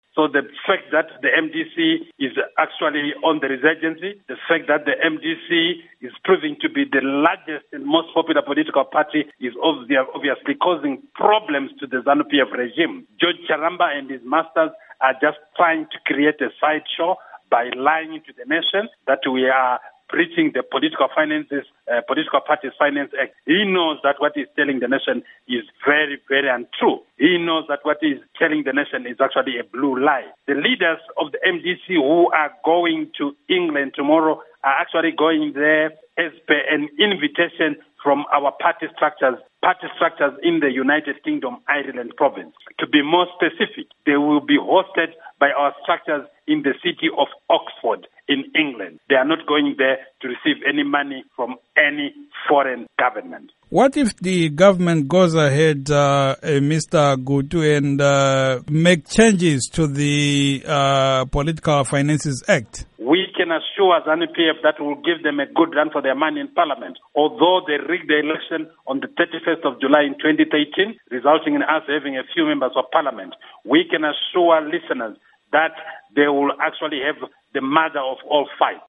Interview With Obert Gutu